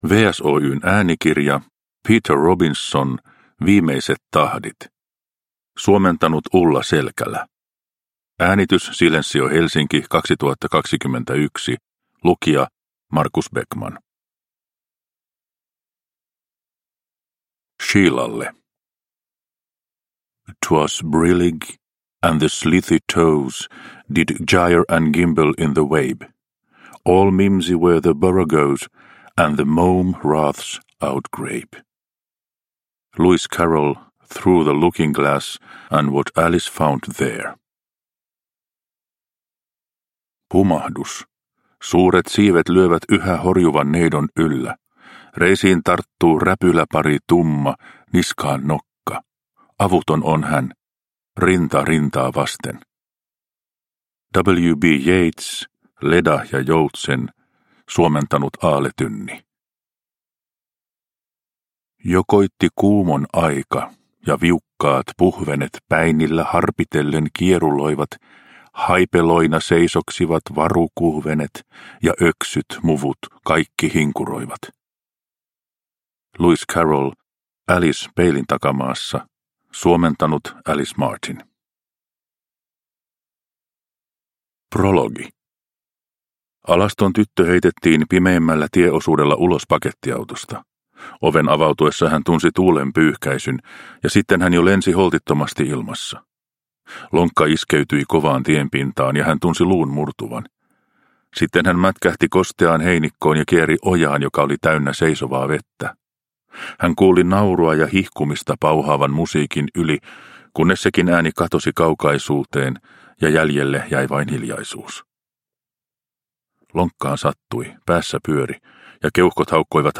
Viimeiset tahdit – Ljudbok – Laddas ner